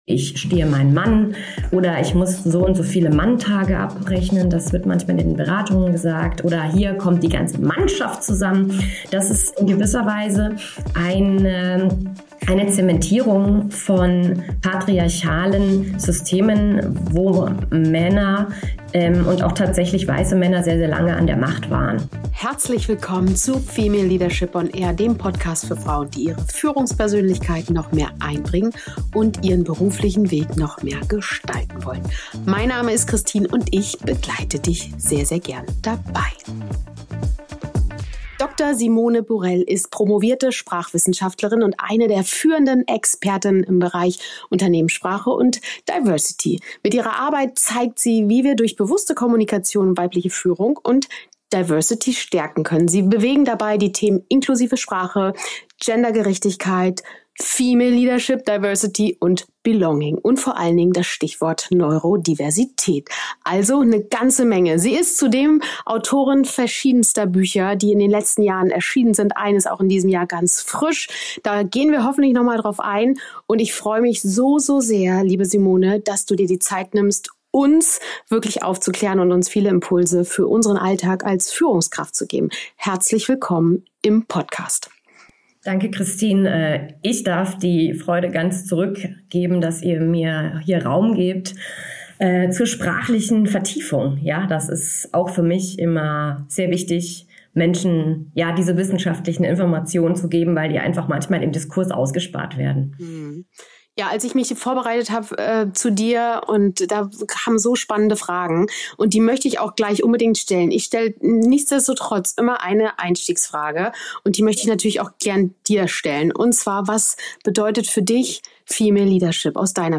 Und mit genau dieser Pionierin durfte ich ein spannendes Gespräch führen.